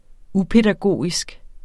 Udtale [ ˈu- ]